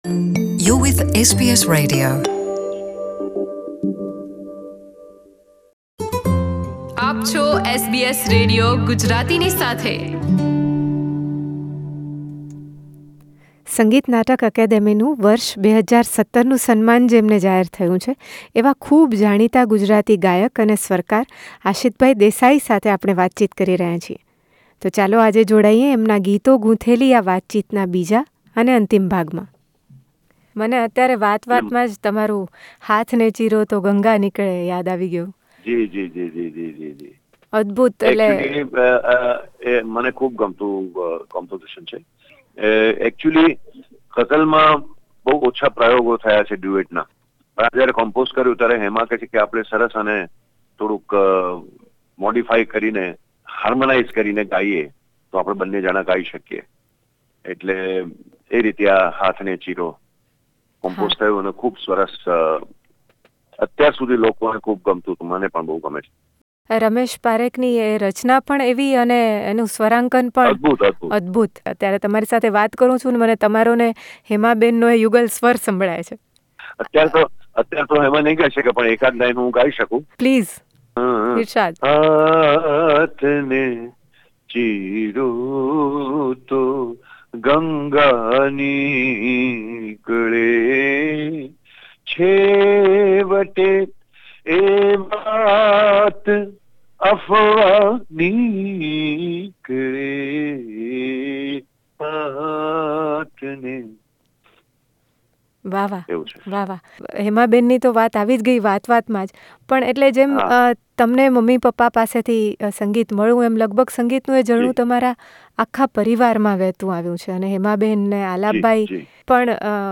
Ashit Desai, an acclaimed Gujarati singer, and composer says there are only a few countries left where we are yet to bring our music. This last part of his conversation is filled with some melodious lines of his very well- known duet with his wife and co-singer Hema Desai.